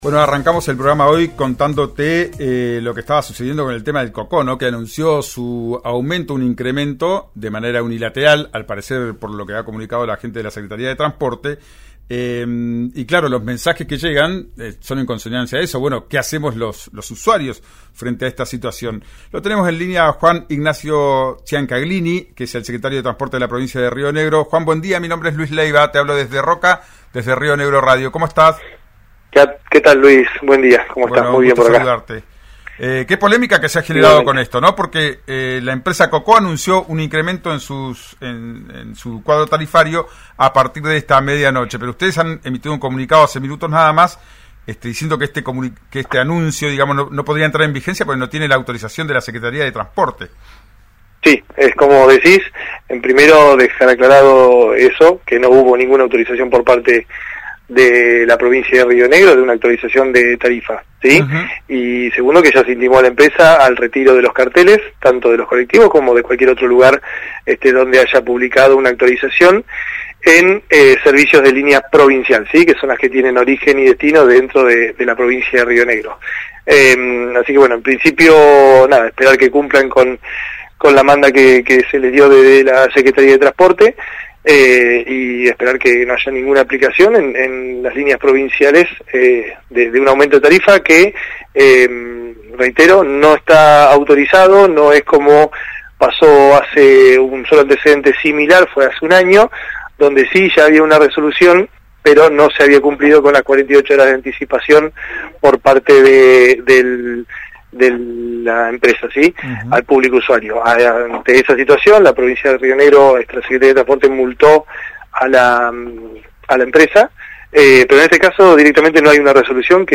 Escuchá al subsecretario de Transporte de Río Negro, Juan Ignacio Ciancaglini, en «Ya es tiempo» por RÍO NEGRO RADIO: